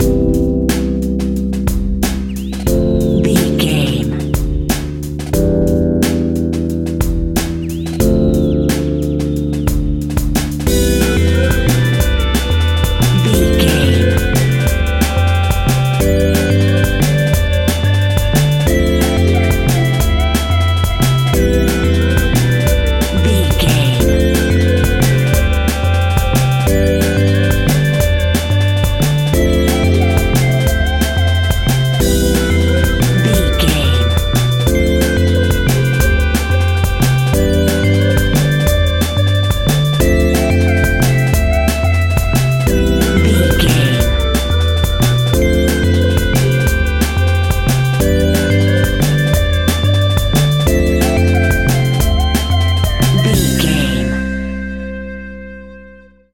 Ionian/Major
Fast
energetic
high tech
uplifting
hypnotic
frantic
drum machine
synthesiser
electronic
sub bass
synth bass